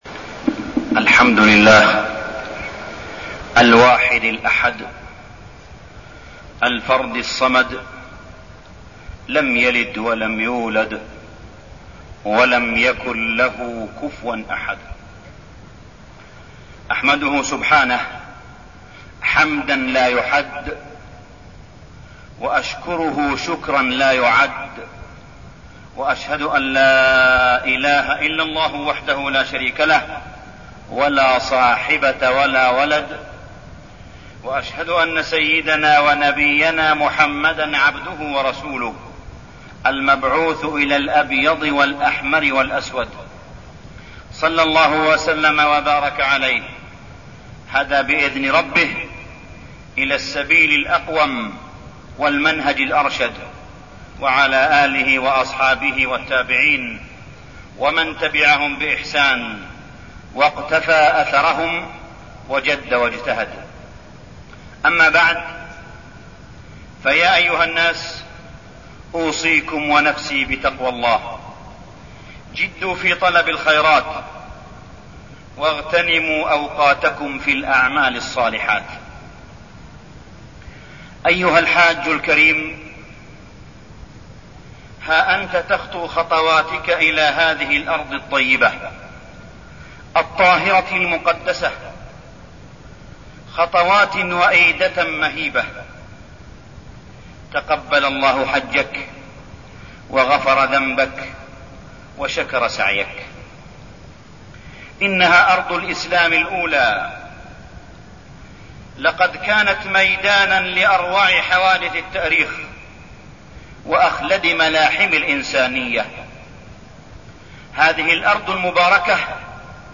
تاريخ النشر ١٨ ذو القعدة ١٤١٤ هـ المكان: المسجد الحرام الشيخ: معالي الشيخ أ.د. صالح بن عبدالله بن حميد معالي الشيخ أ.د. صالح بن عبدالله بن حميد إخواننا في فلسطين والبوسنة